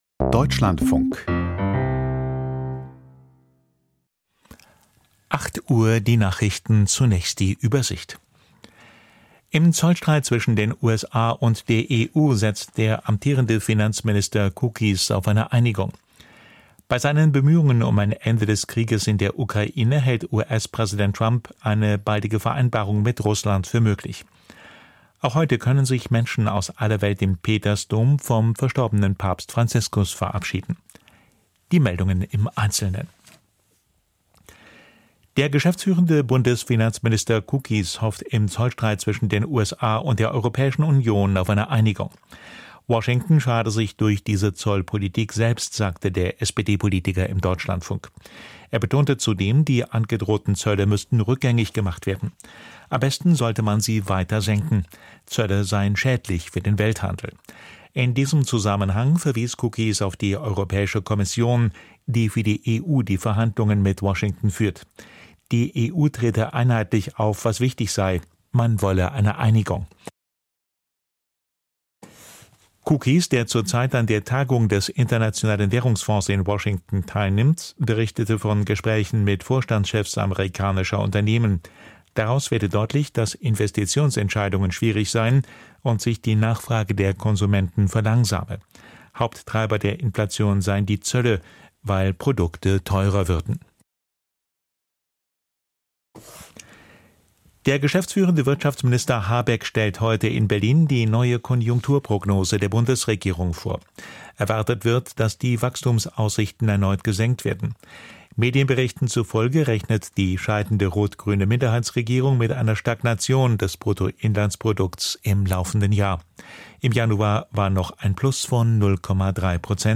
Die Deutschlandfunk-Nachrichten vom 24.04.2025, 08:00 Uhr